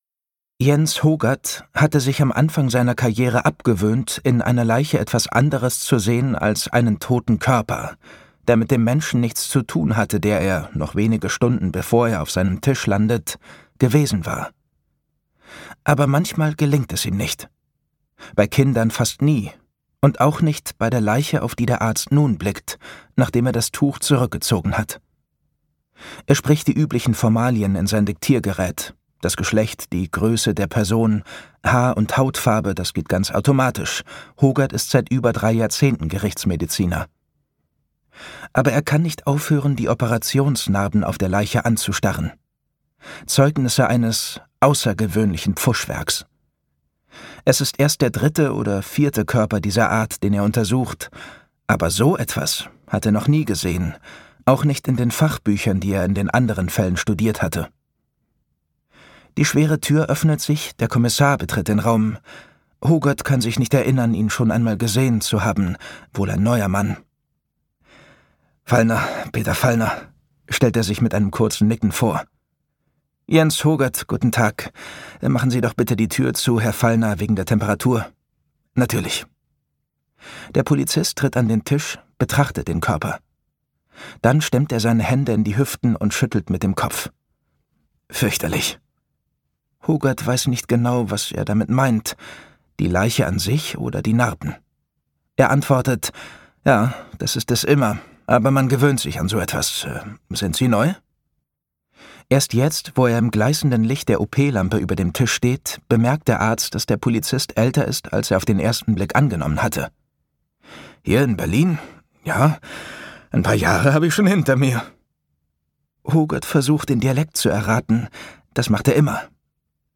Aufsteiger - Peter Huth | argon hörbuch
Gekürzt Autorisierte, d.h. von Autor:innen und / oder Verlagen freigegebene, bearbeitete Fassung.